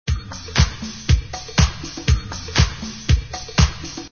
danceOne_beatz01.mp3